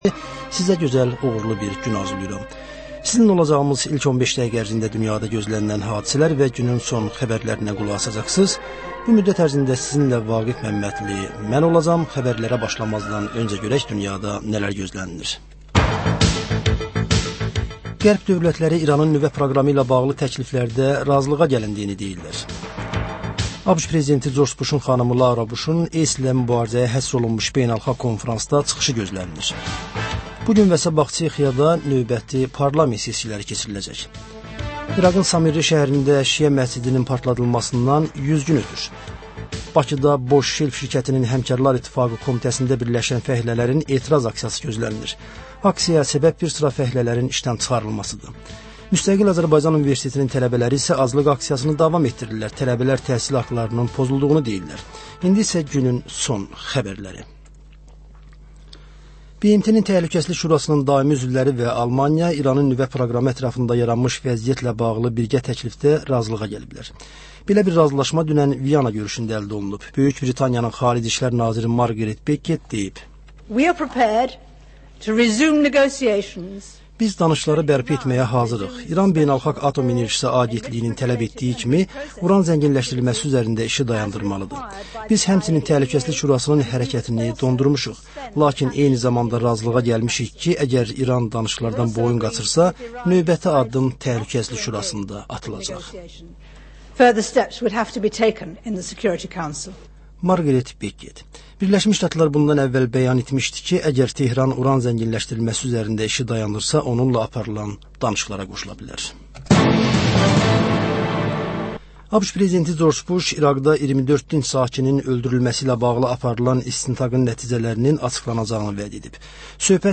Bugün nələr gözlənir, nələr baş verib? Xəbər, reportaj, müsahibə.